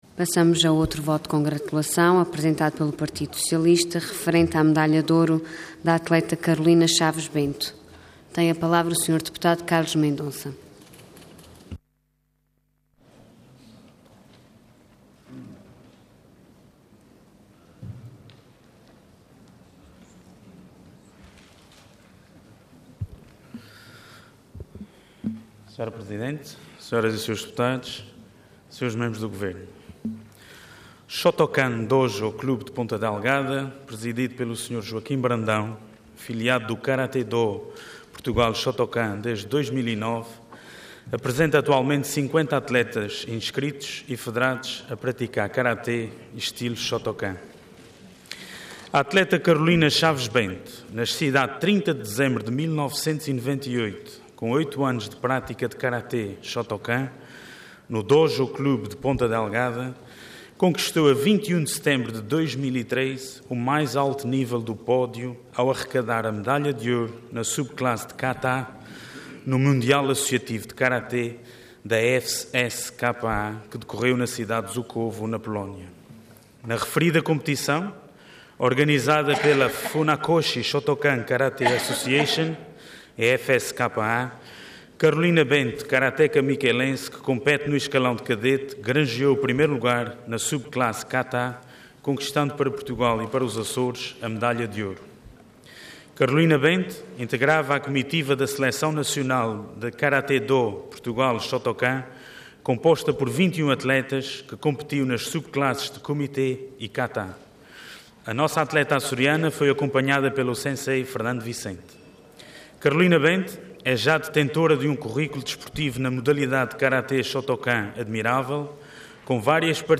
Intervenção Voto de Congratulação Orador Carlos Mendonça Cargo Deputado Entidade PS